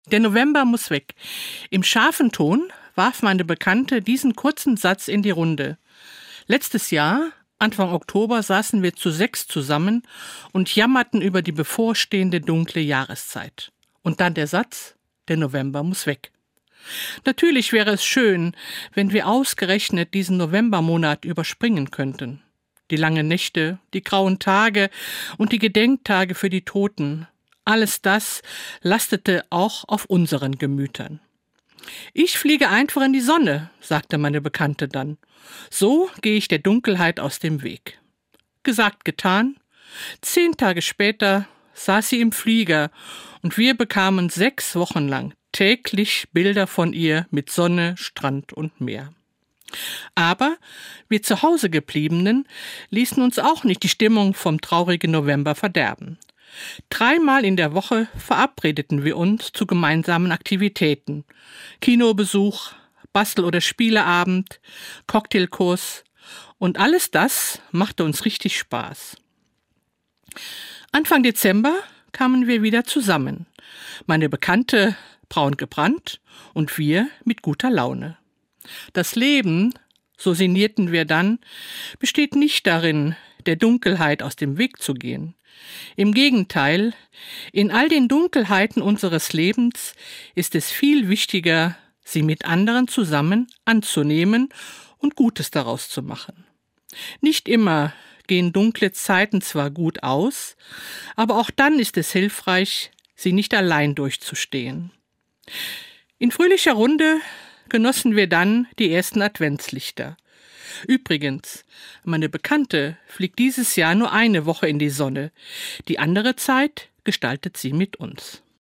Das christliche Wort zum Alltag